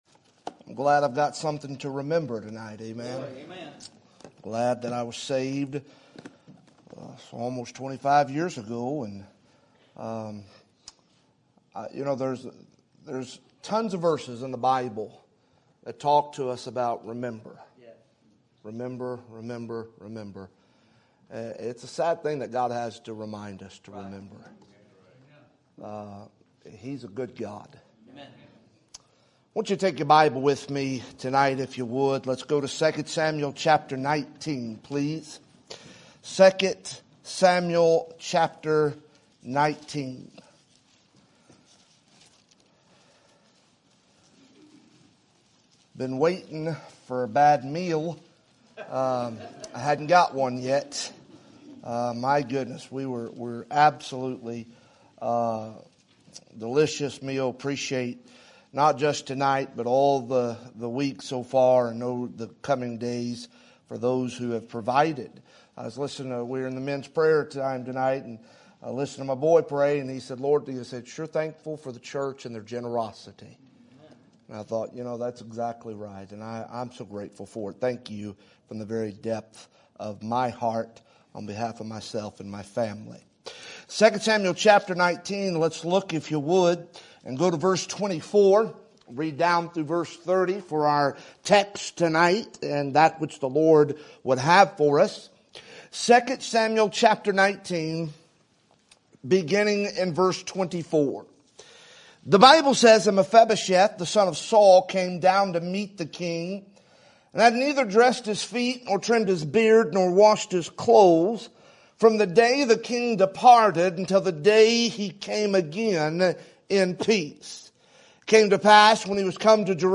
Sermon Date
Sermon Topic: Spring Revival Sermon Type: Special Sermon Audio: Sermon download: Download (21.94 MB) Sermon Tags: 2 Samuel Revival David Mephibosheth